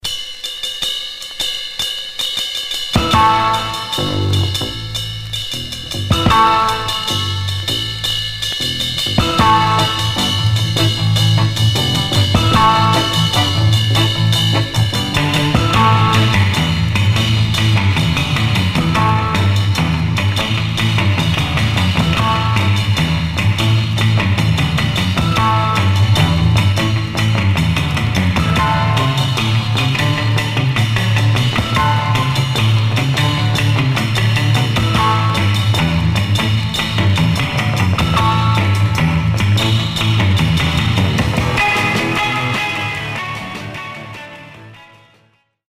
R & R Instrumental